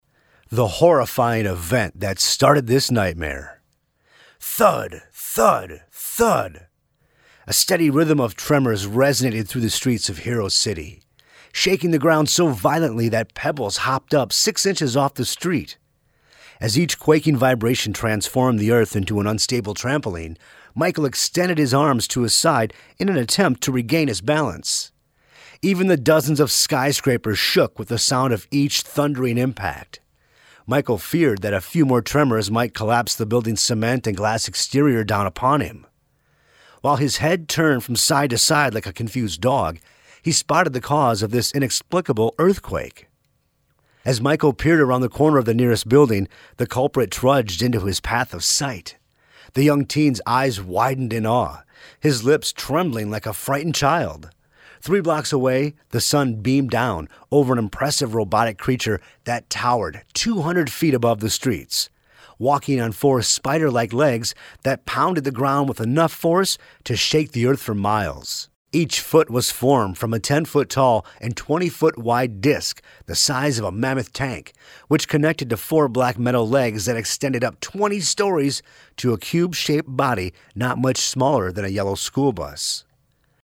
Male
Yng Adult (18-29), Adult (30-50)
My voice is best described as conversational, compelling and friendly. Can be comedic and sarcastic on the drop of a dime, yet overly- friendly and smooth at the same time.
Audiobooks
Words that describe my voice are Strong, Fast, Smooth.